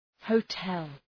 Προφορά
{həʋ’tel}